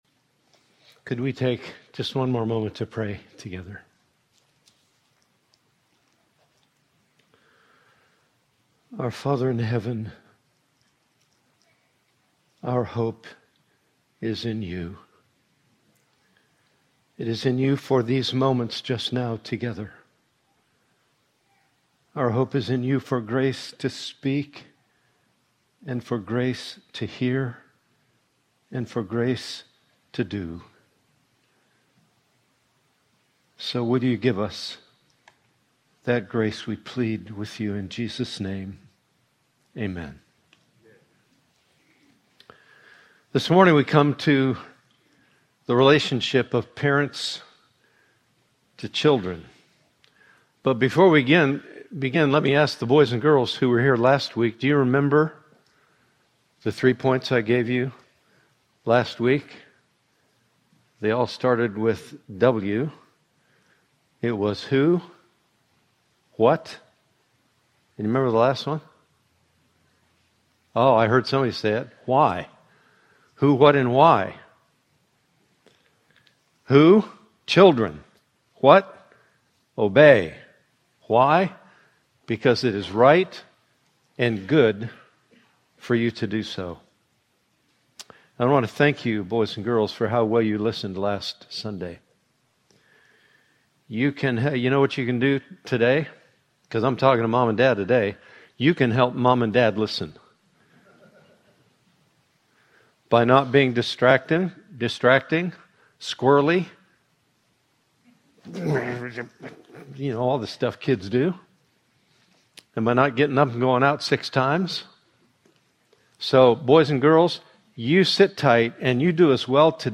Sermons from Heritage Baptist Church in Owensboro, KY
Sermons